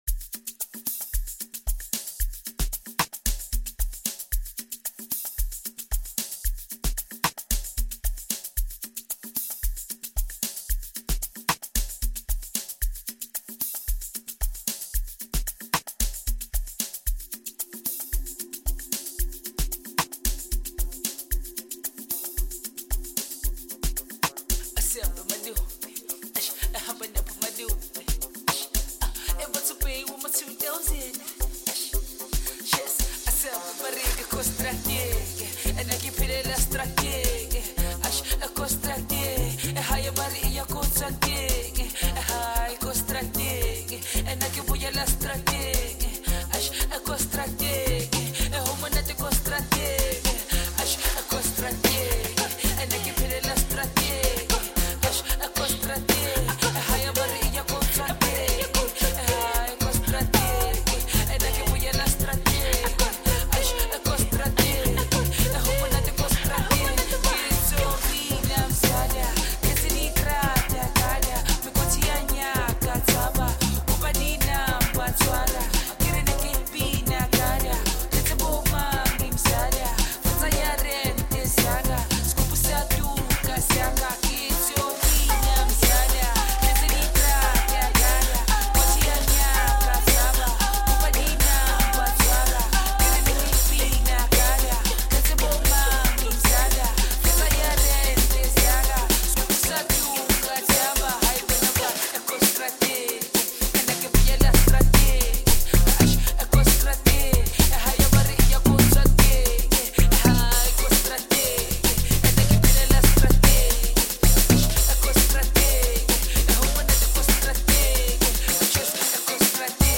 is a mesmeric jazz-infused masterpiece